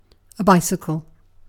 Ääntäminen
IPA : /ˈbaɪsɪkəl/